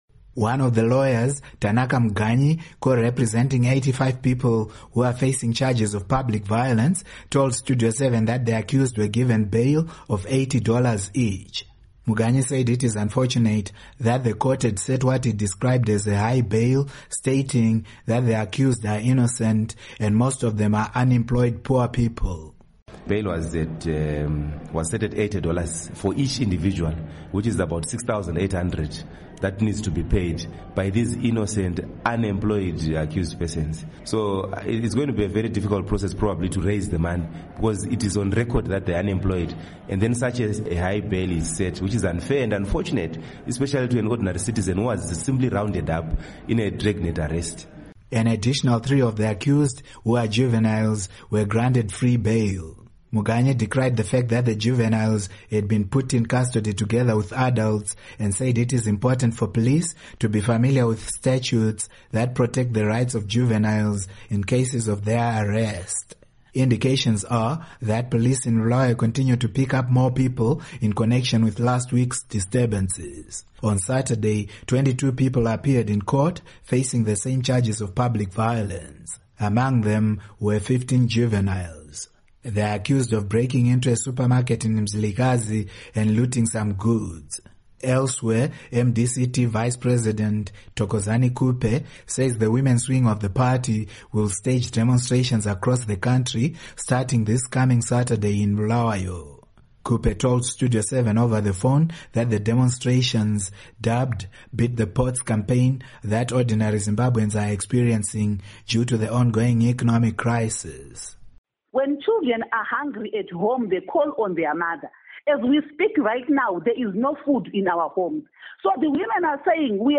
Report on Bulawayo Bail